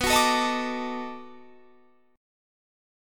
B7#9 chord